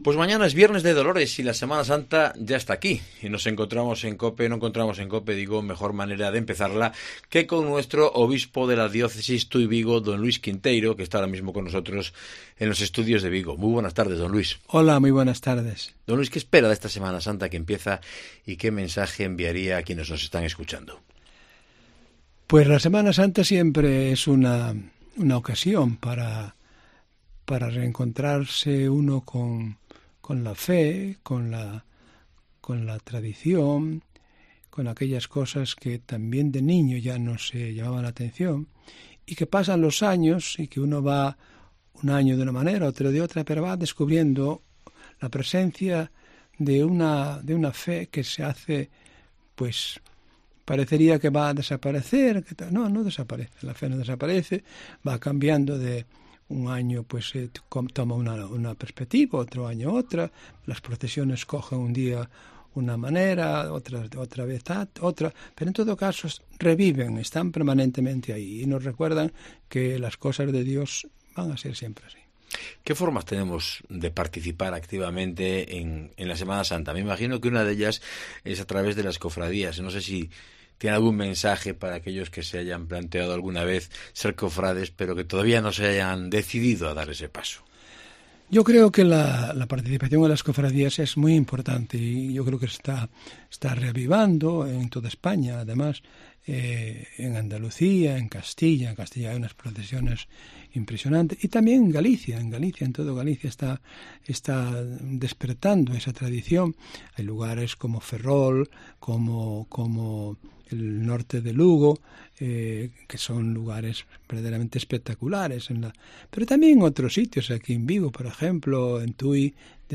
Entrevista con don Luis Quinteiro, Obispo de la Diócesis Tui-Vigo
El Obispo de la Diócesis Tui-Vigo, don Luis Quinteiro, ha estado este jueves en los estudis de COPE Vigo, donde ha reflexionado sobre la Semana Santa, que "siempre es una ocasión para reencontrarse con la fe, con la tradición, con aquellas cosas que ya de niños nos llamaban la atención", ha dicho.